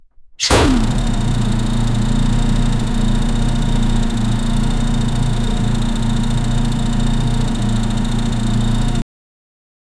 exhaust-sound-of-turbo-in-qapiulmy.wav